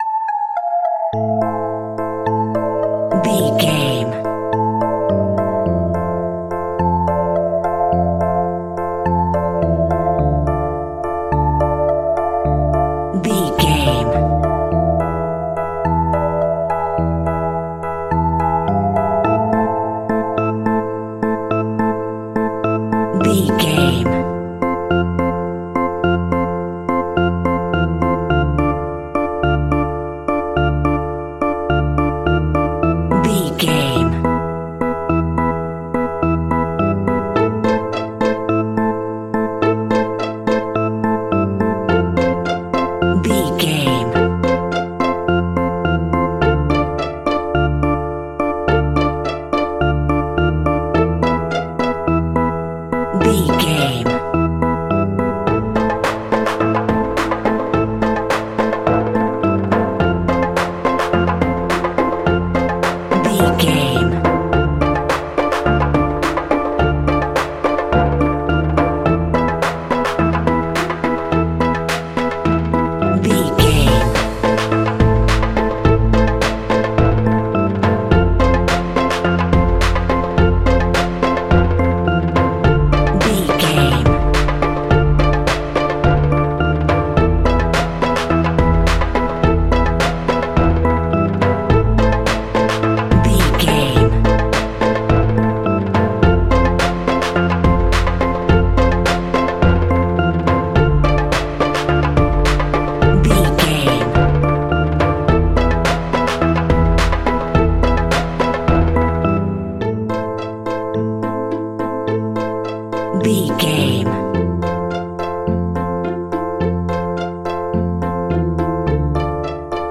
Thriller
Aeolian/Minor
ominous
eerie
synthesiser
electric piano
strings
drums
percussion
creepy
horror music